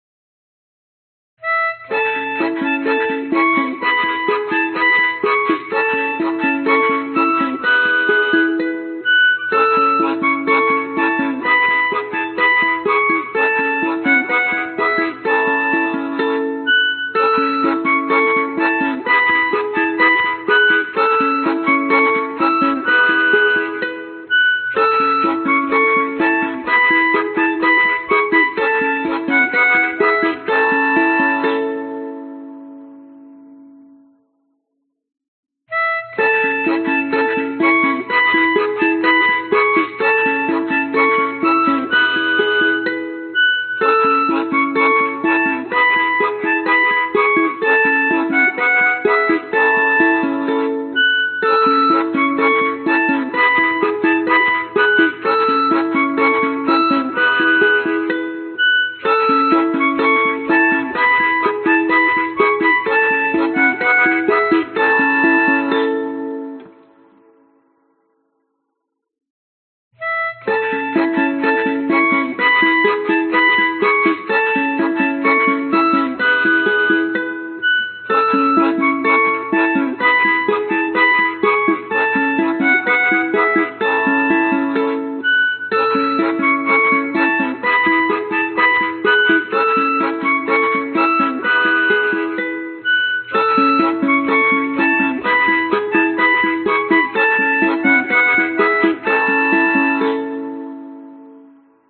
标签： 巴尔干 民间 竖琴 珠宝 世界
声道立体声